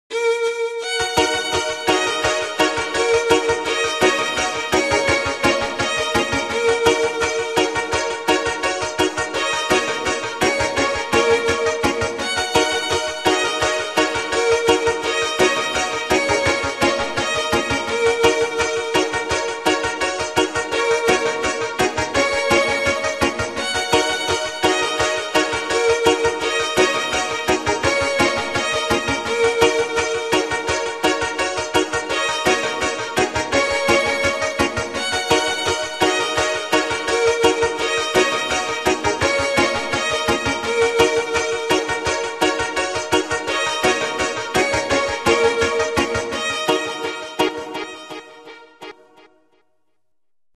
but they just sound like standard phone ringtones to me